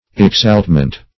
exaltment - definition of exaltment - synonyms, pronunciation, spelling from Free Dictionary
exaltment - definition of exaltment - synonyms, pronunciation, spelling from Free Dictionary Search Result for " exaltment" : The Collaborative International Dictionary of English v.0.48: Exaltment \Ex*alt"ment\, n. Exaltation.